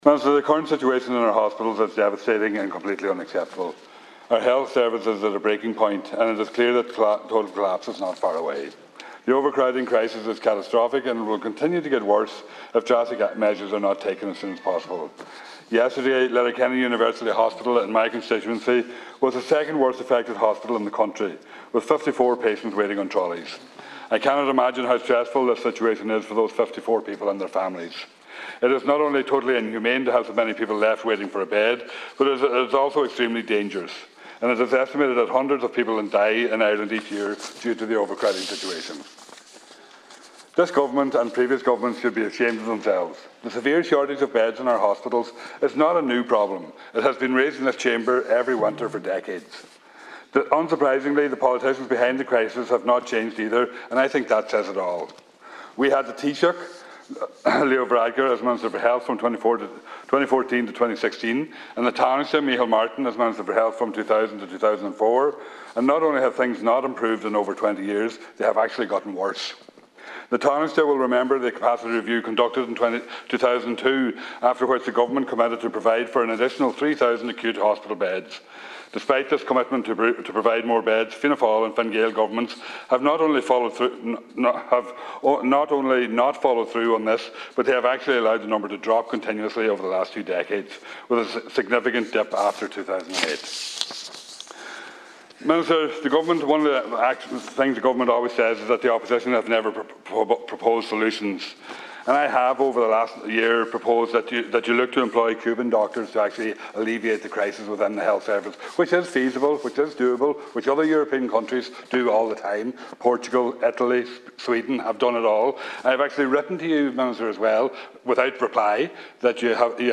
Speaking during a debate on the crisis in the health service, Deputy Thomas Pringle said he’s written to Minister Stephen Donnelly on a number of occasions suggesting that the government bring doctors into the state from Cuba.